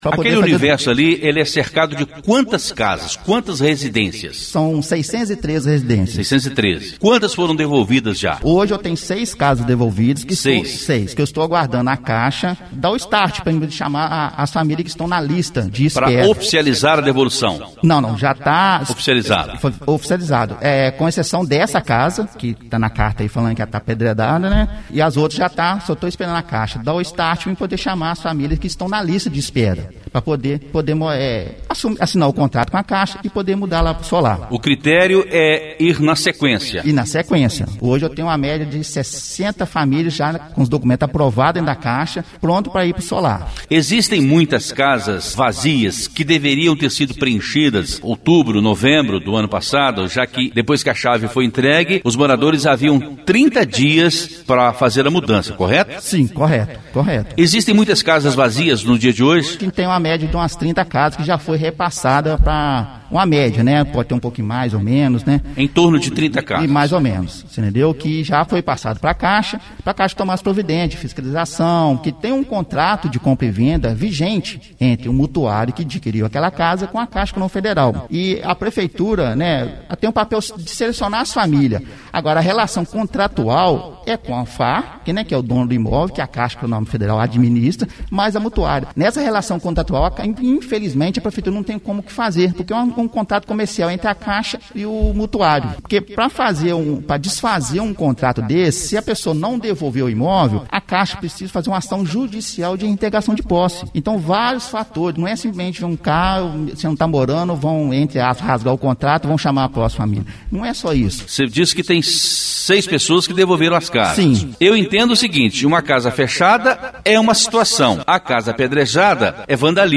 Rádio Educadora AM/FM – entrevista editada/reduzida